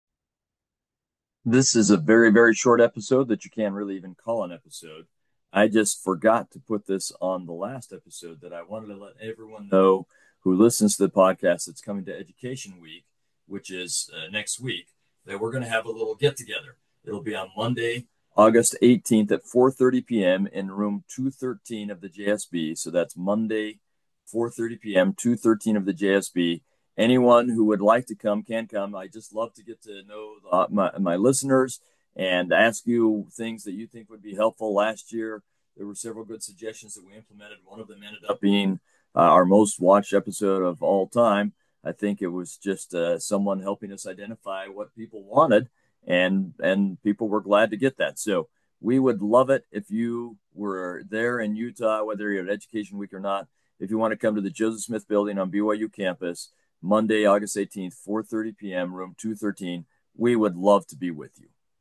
We interview both experts (people with language, archaeological, historical backgrounds, etc.), and lay folks, and explore times when the scriptures became real to them. This is done from the viewpoint of members of the Church of Jesus Christ of Latter-day Saints.